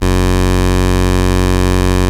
OSCAR 13 F#2.wav